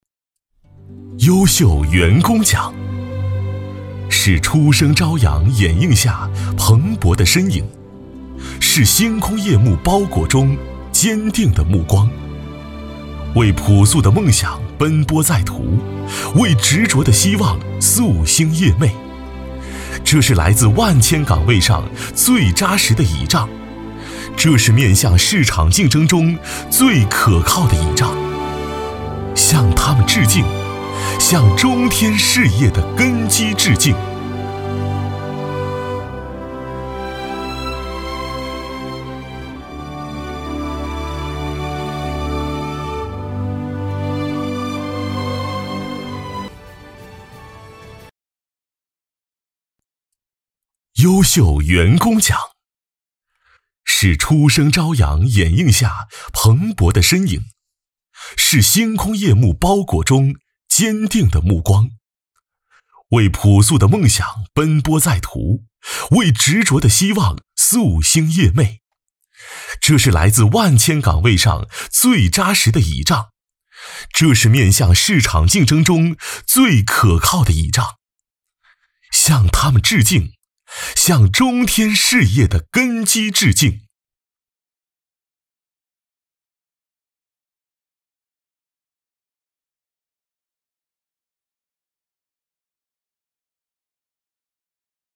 全风格男配